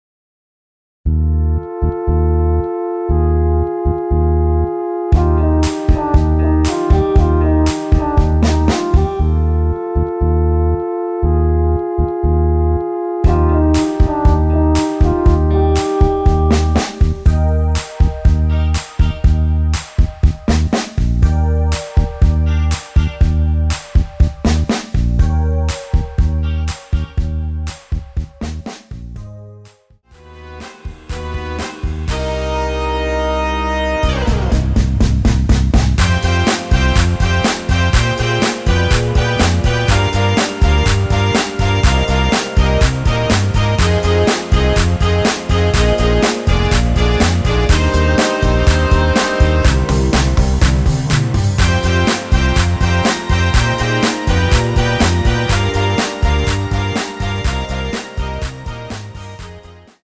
Eb
노래방에서 음정올림 내림 누른 숫자와 같습니다.
앞부분30초, 뒷부분30초씩 편집해서 올려 드리고 있습니다.
중간에 음이 끈어지고 다시 나오는 이유는